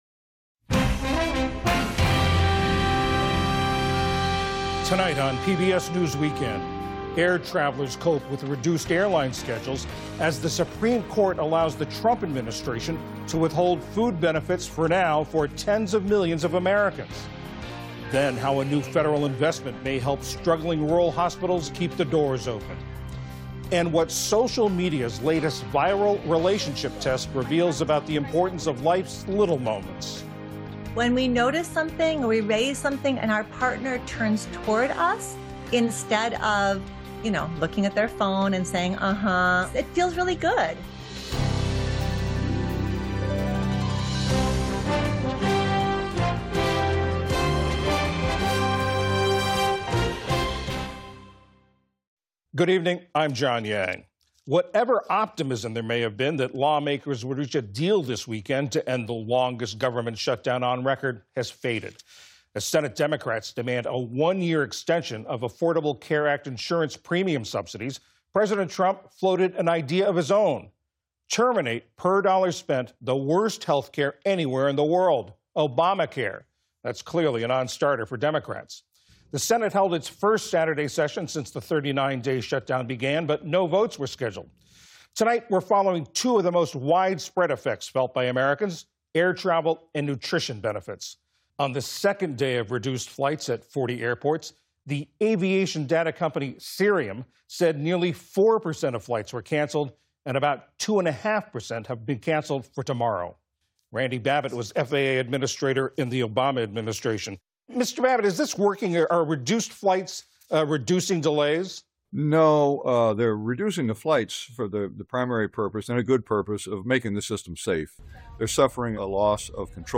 Listen to the complete PBS News Hour, specially formatted as a podcast. Published each night by 9 p.m., our full show includes every news segment, every interview, and every bit of analysis as our television broadcast.